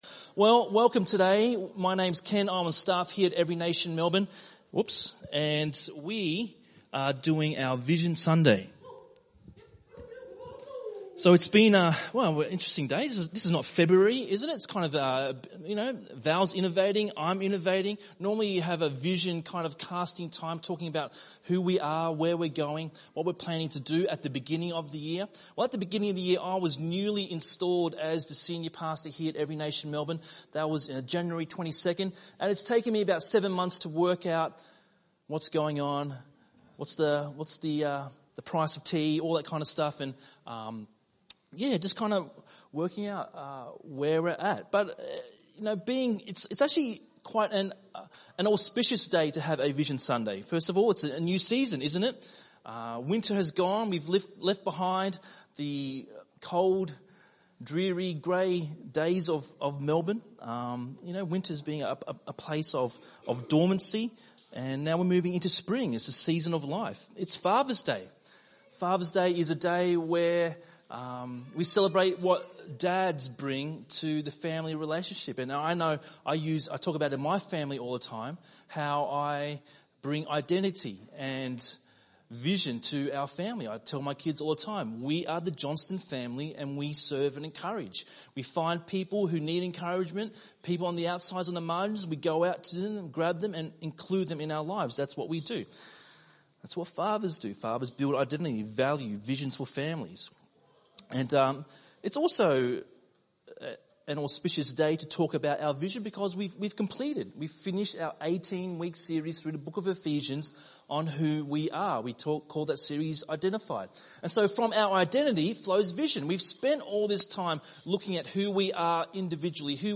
by enmelbourne | Sep 3, 2018 | ENM Sermon